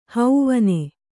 ♪ hauvane